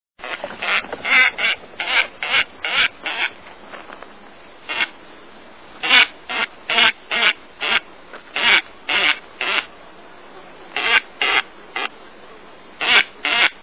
White-faced Ibis
Bird Sound
Nasal moaning "urm." Croaks and rattles at breeding colony.
White-facedIbis.mp3